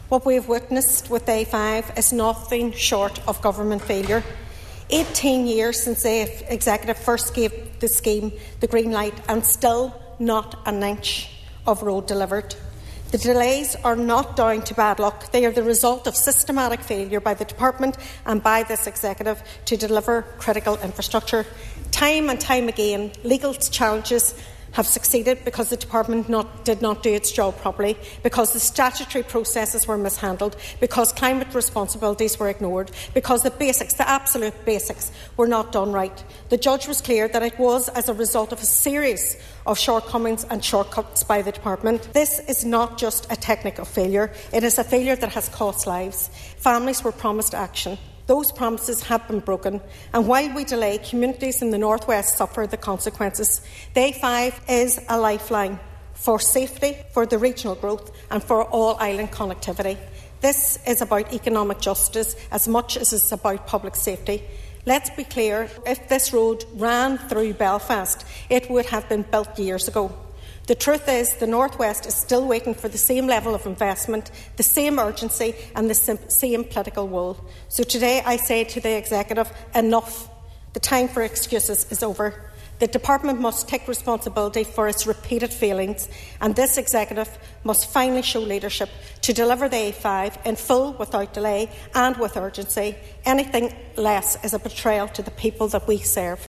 She told MLAs the Executive and the Department must immediately address the issues raised in the judgement and ensure the A5 is delivered without any more undue delay…………